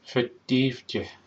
In other parts of Ireland it would be pronounced something like /
fa.d̪ˠiːv.dʲe/, though other words are generally used: